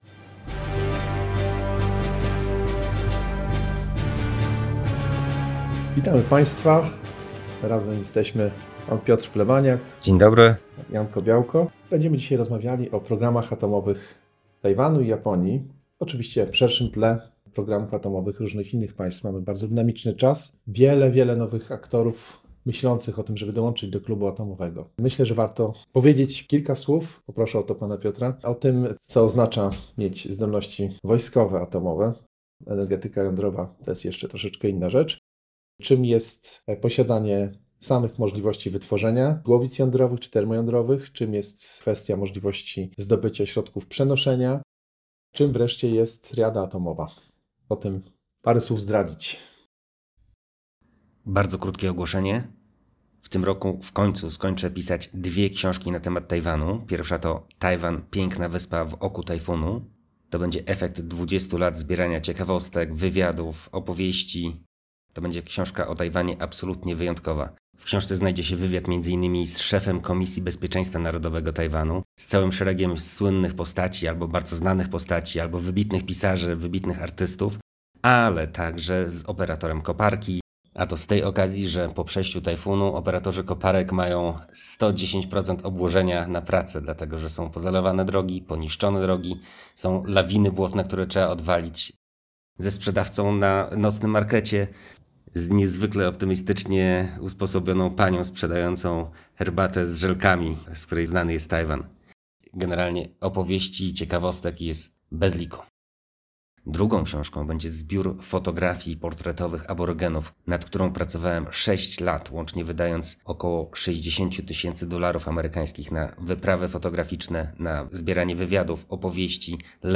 Plik po rekonstrukcji w 2025 roku
Usunięcie usterek dykcji autora jest niezwykle czasochłonne.